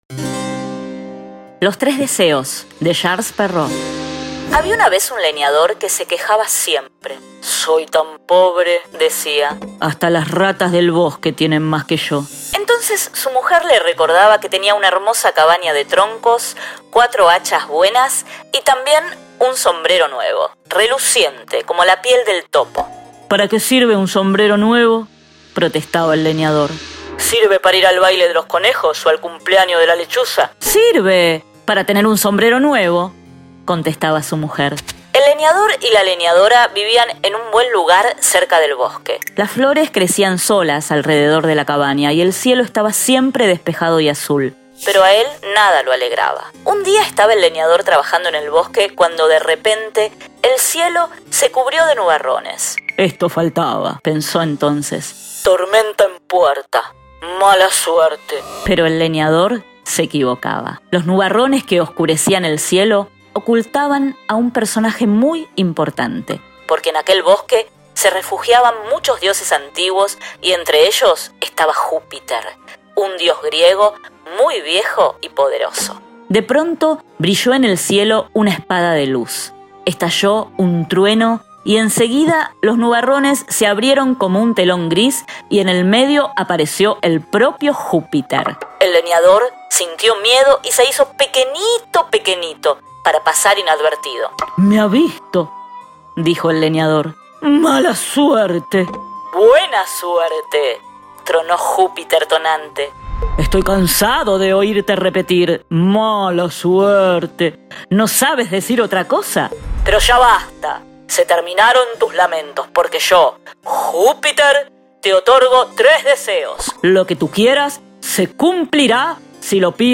Cuentos contados: Los tres deseos de Charles Perrault